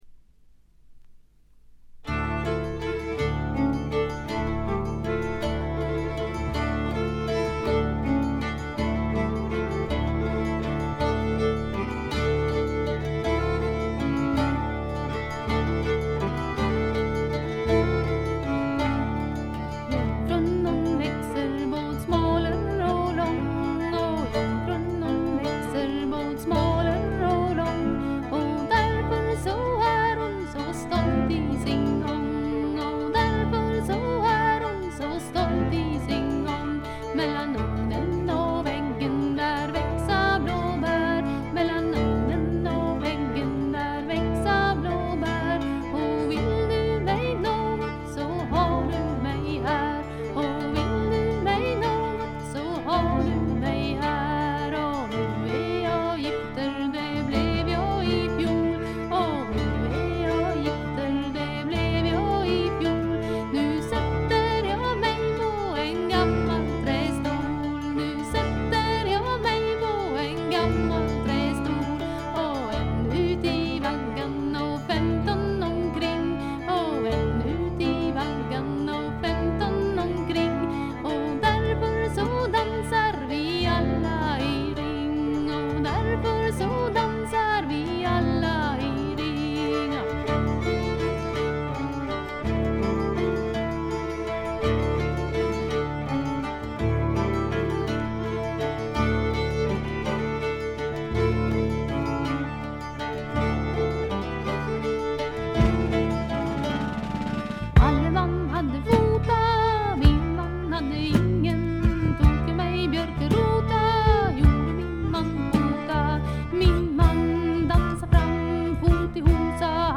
軽微なチリプチ少し。
スウェーデンのトラッド・フォーク・グループ。
試聴曲は現品からの取り込み音源です。
Recorded At - Metronome Studio, Stockholm